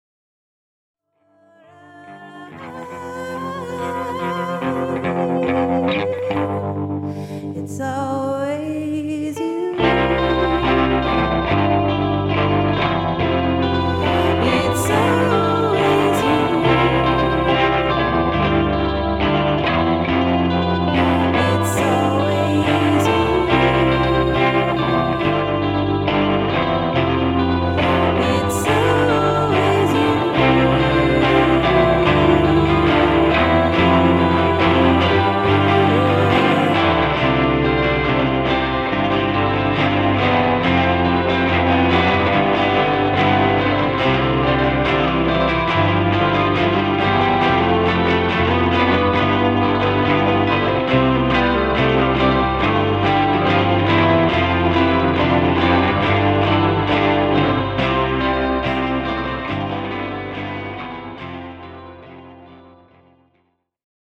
keening, crooning, howling vocals